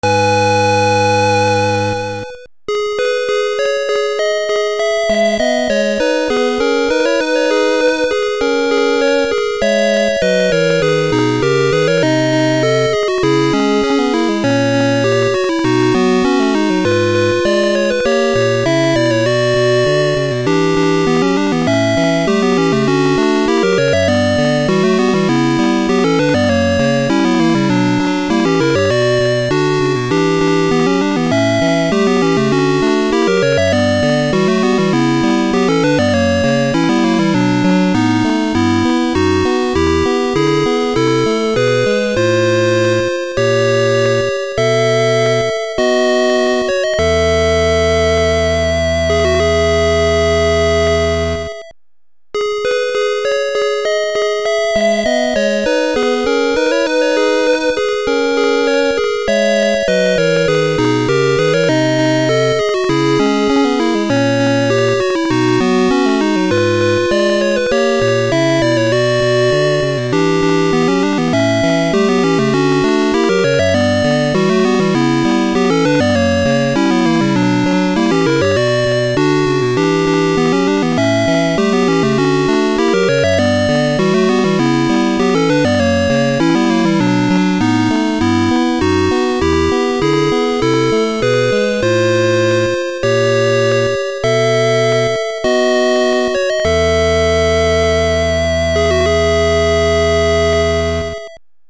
Sounds very much like a "shrine" theme.